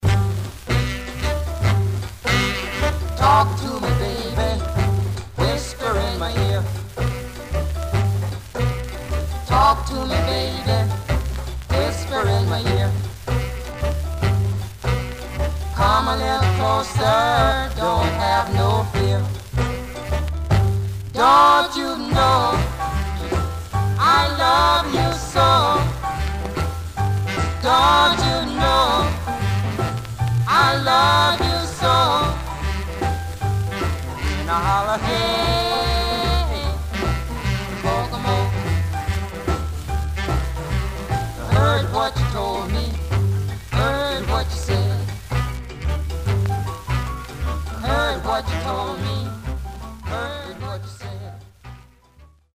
Surface noise/wear
Mono
Rythm and Blues